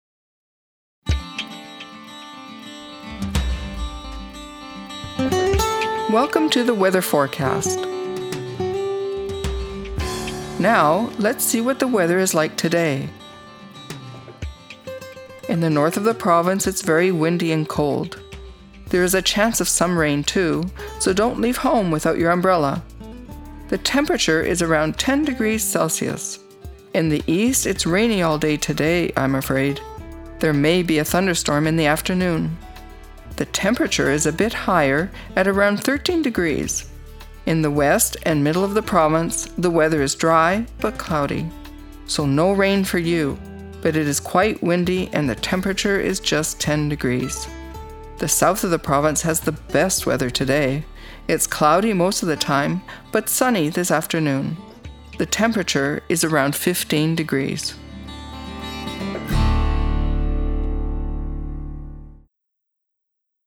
First, read the following questions : When is this weather forecast for? Which part of the province will receive the most rain?
What does the meteorologist recommend?